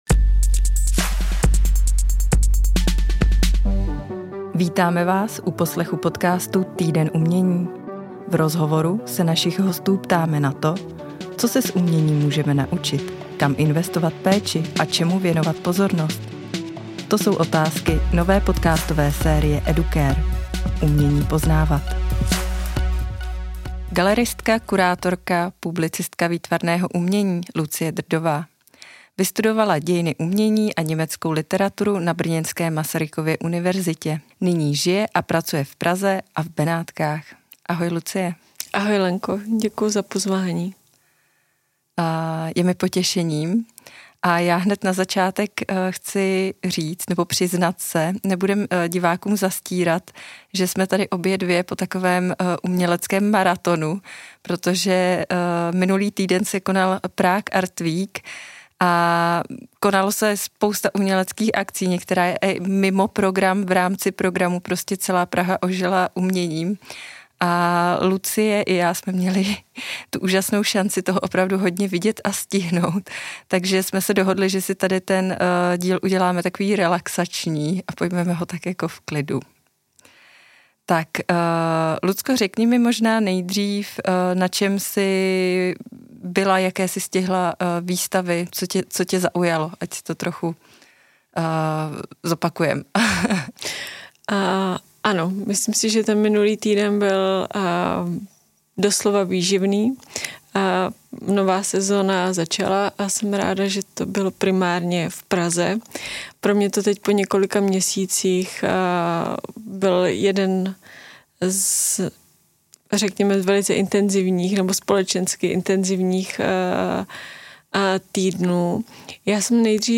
V rozhovoru se dozvíte, jak multimediální projekt Confluence vzniknul, ale i to jak náročné je zahraniční výstavu v Benátkách, během nejexponovanějšího období Benátského bienále připravovat.